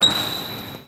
Chirrido de una zapatilla deslizándose por el parquet
chirrido
Sonidos: Acciones humanas